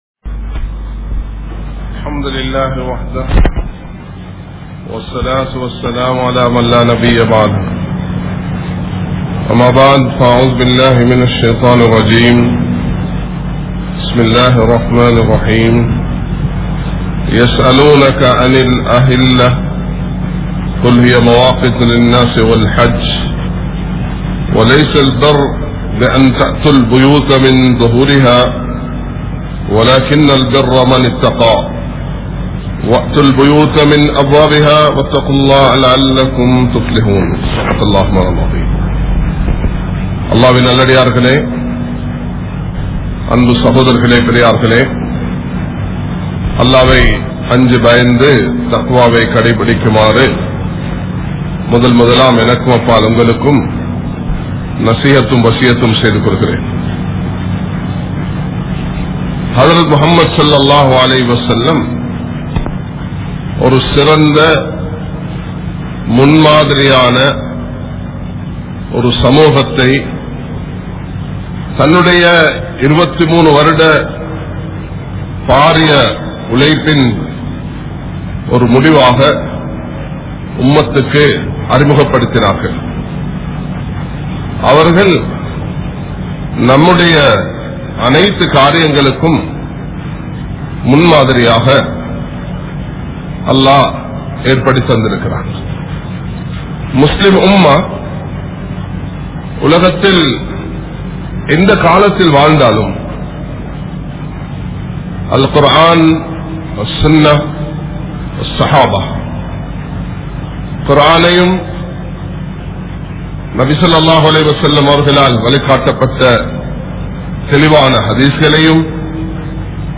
Sirantha Samooham Ethu? (சிறந்த சமூகம் எது?) | Audio Bayans | All Ceylon Muslim Youth Community | Addalaichenai
Kollupitty Jumua Masjith